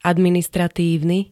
administratívny [-n-t-] -na -ne príd.
Zvukové nahrávky niektorých slov